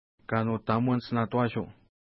Pronunciation: ka:nu:ta:mwa:nts na:twa:ʃu: